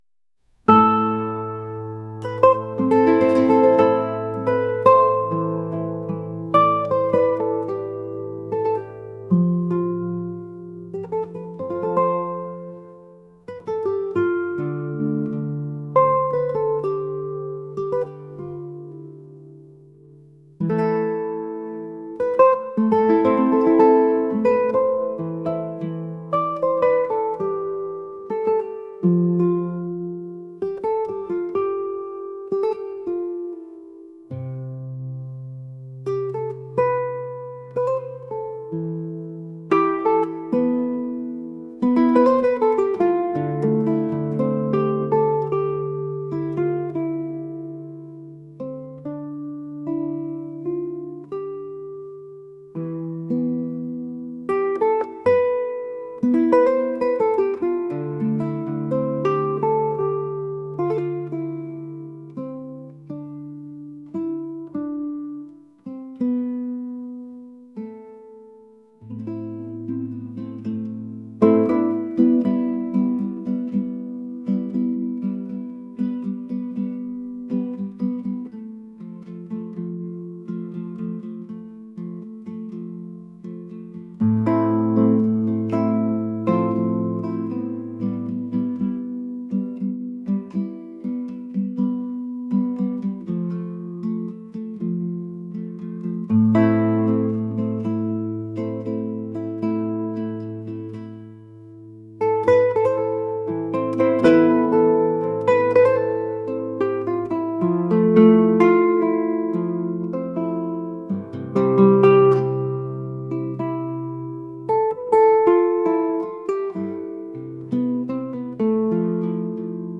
soulful | folk | classical